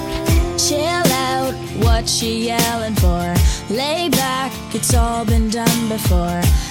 辅音/t/与/j/连读时，被同化为/t∫/